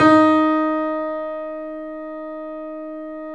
Index of /90_sSampleCDs/E-MU Producer Series Vol. 5 – 3-D Audio Collection/3D Pianos/YamaMediumVF04